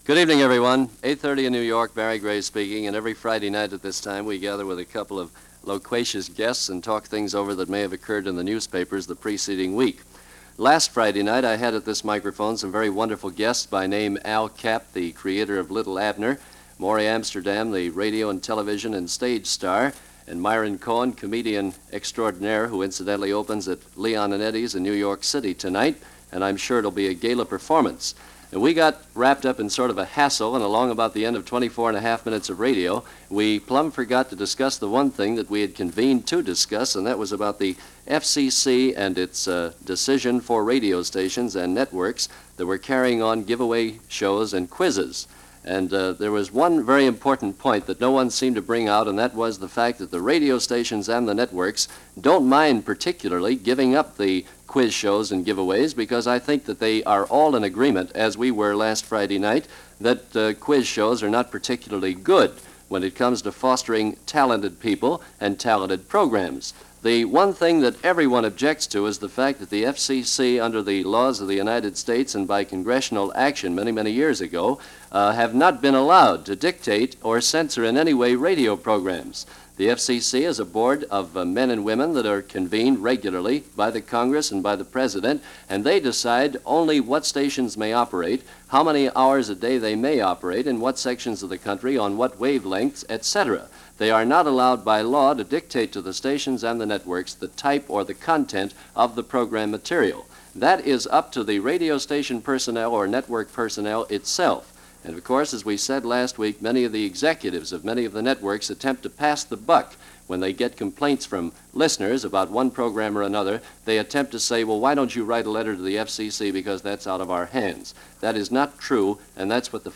Before the days of phone-in talk shows, Pop Psychologists and conspiracy theorists, Radio was getting its feet wet during the post World War 2 years with conversational programs – people sitting around a microphone discussing things.